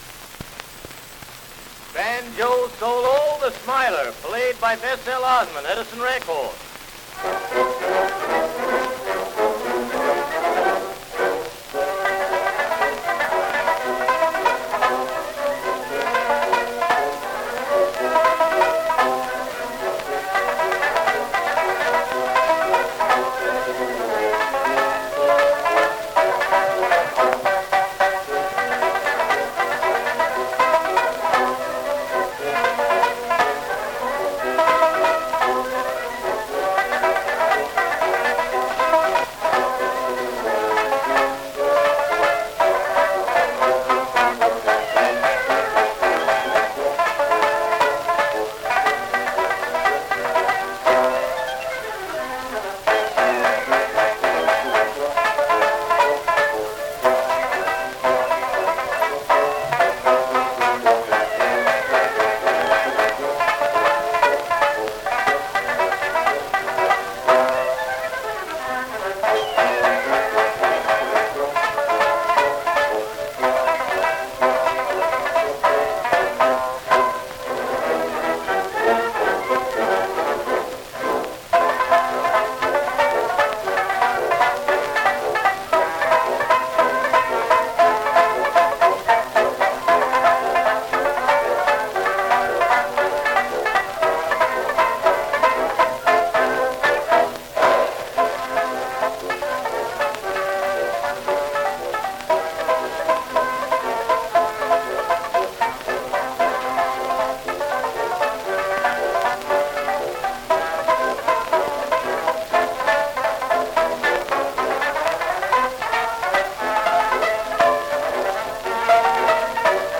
am Banjo mit Bläserbegleitung